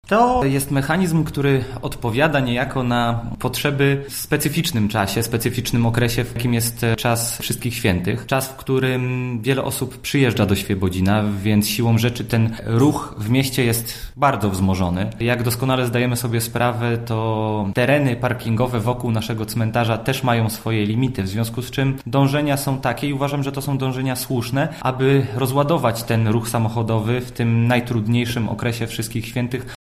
Tomasz Sielicki, burmistrz Świebodzina, mówi, że takie rozwiązanie w tamtym roku cieszyło się dużym zainteresowaniem, zwłaszcza, ze korzystając z takiego przejazdu nie ma potrzeby kupna biletu: